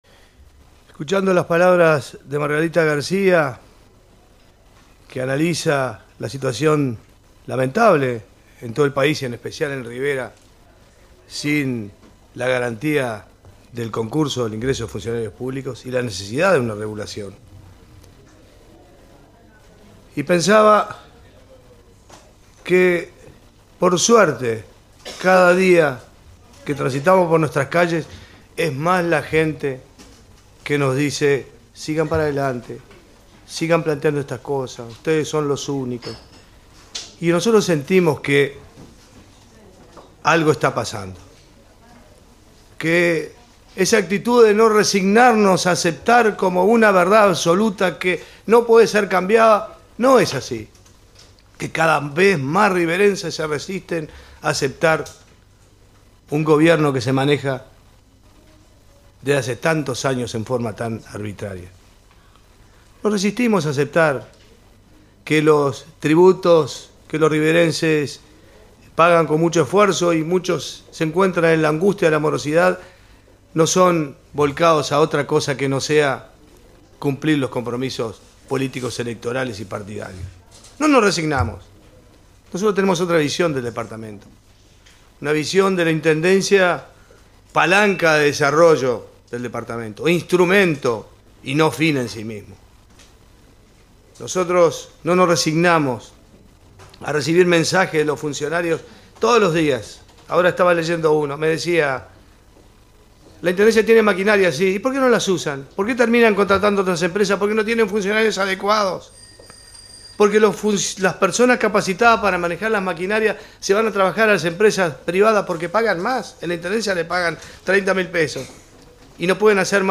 4-Sr. Edil Fernando Araújo: